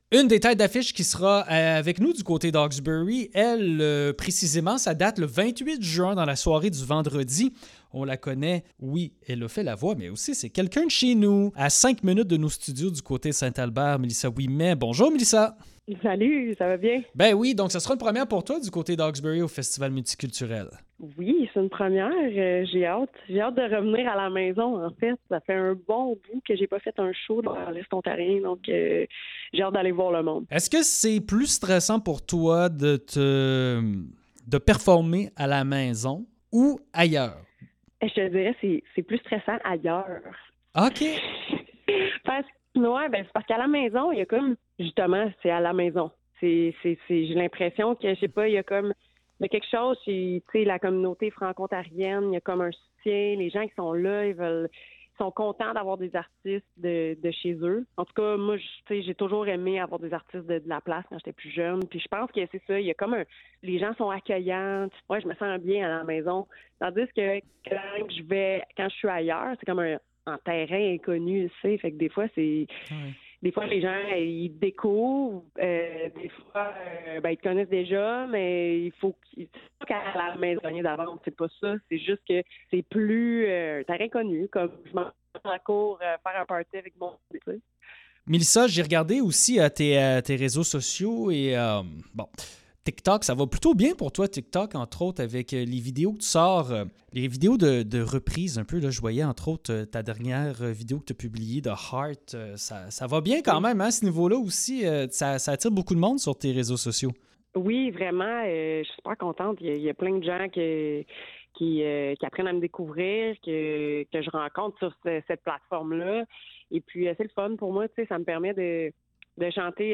Entrevue FMH